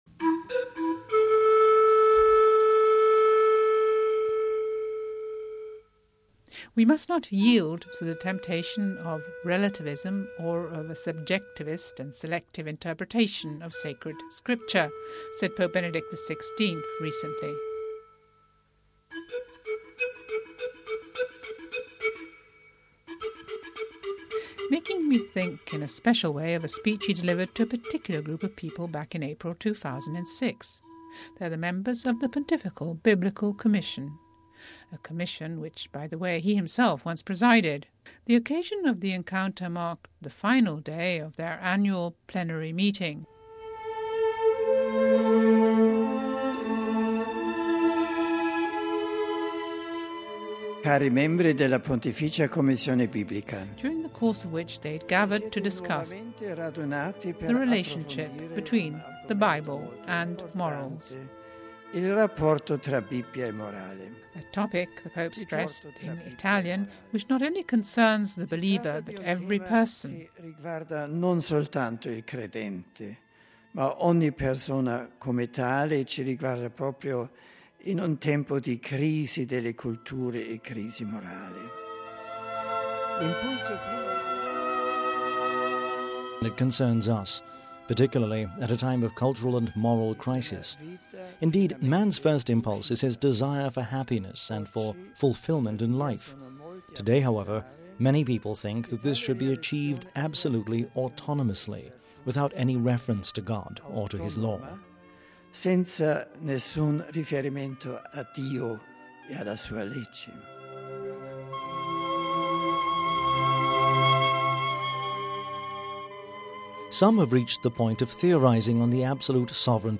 BIBLE AND MORALS Home Archivio 2007-03-27 15:18:02 BIBLE AND MORALS Listen to one of Pope Benedict's speeches to members of the Pontifical Biblical Commission...